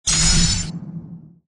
laser.mp3